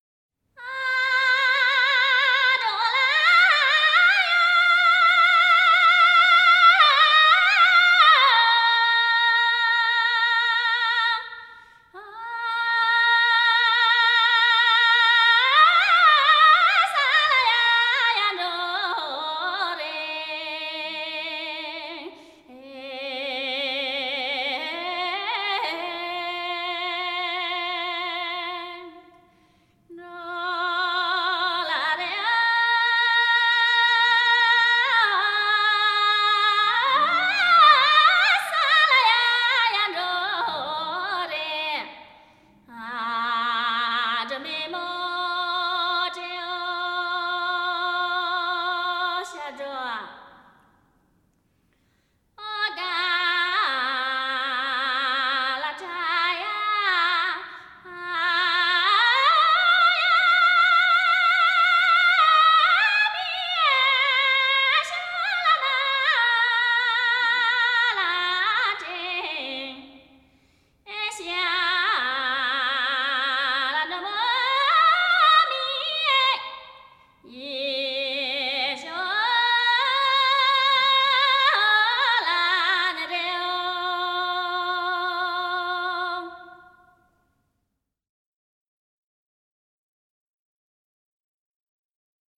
山歌    1:28